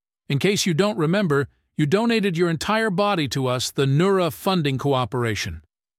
anouncer_intro_03.ogg